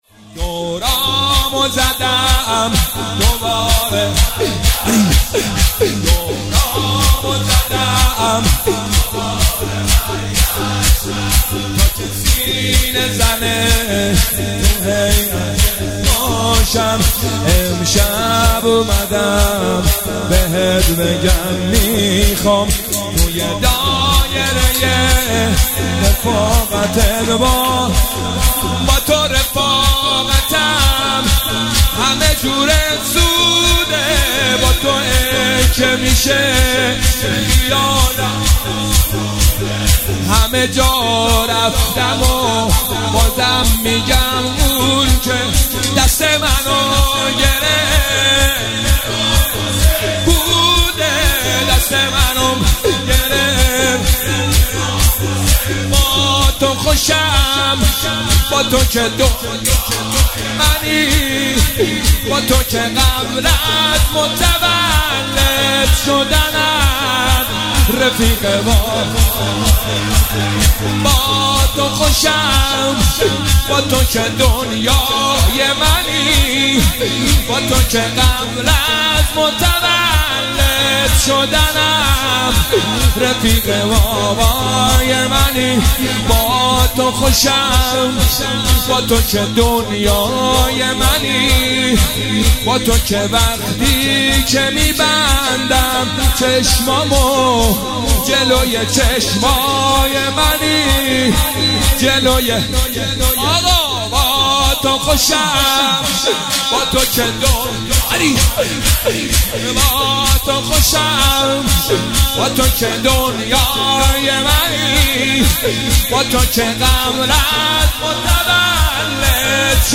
هیئت هفتگی 27 اردیبهشت 1404